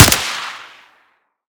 ak74_suppressed_fire1.wav